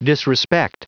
Prononciation du mot disrespect en anglais (fichier audio)
Prononciation du mot : disrespect